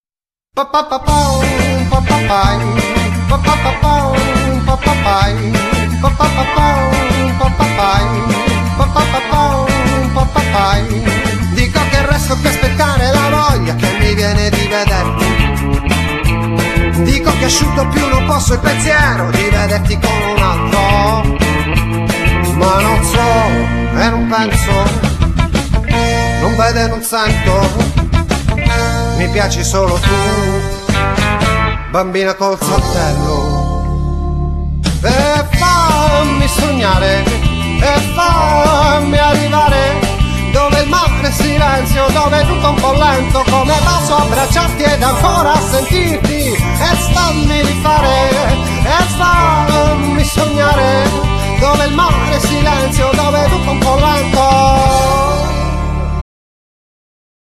Genere : Pop
gruppo pop-funk-blues italiano
chitarre e voce
batteria e cori
basso elettrico.